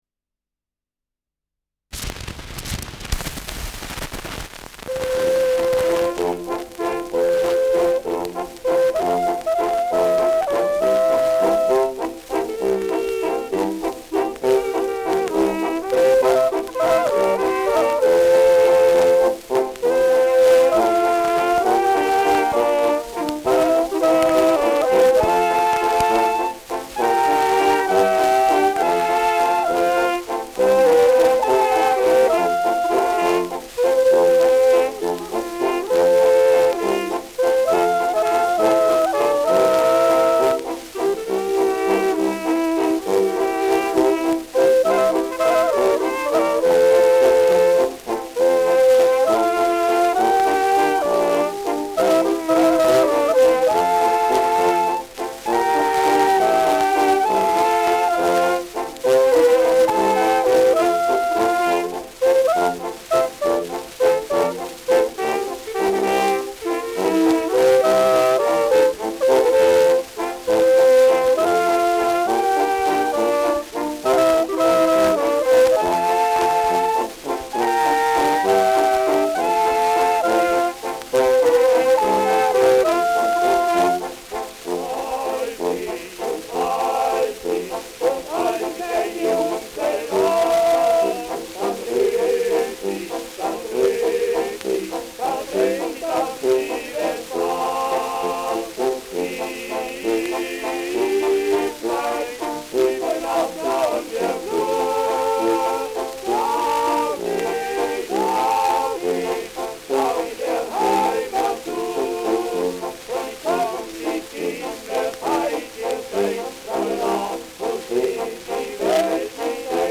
Schellackplatte
Stärkeres Grundrauschen : Vereinzelt leichtes Knacken
Kapelle Die Alten, Alfeld (Interpretation)